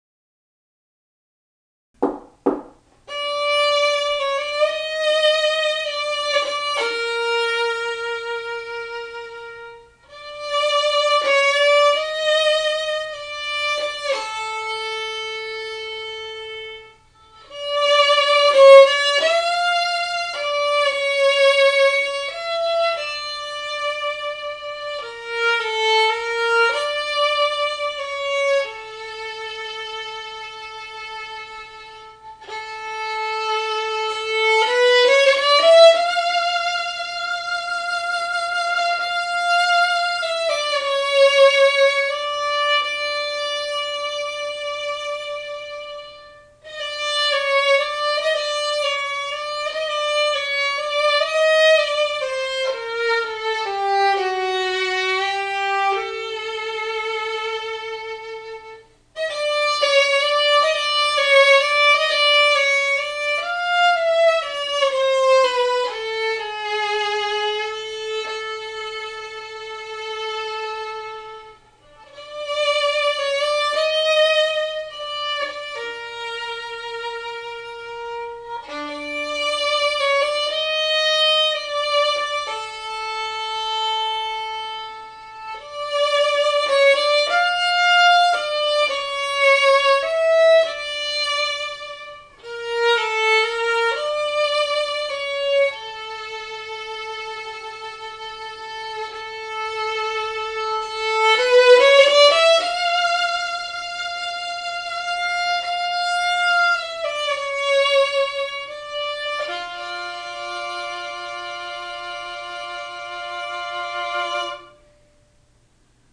The acoustics here in our living room are exceptional, and really help to 'hear' accurately.
Click the four violins below: to hear real samples of "Rich" Tuned violins...